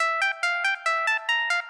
陷阱阿普
描述：简单的陷阱Arp
标签： 140 bpm Trap Loops Synth Loops 295.49 KB wav Key : Unknown
声道立体声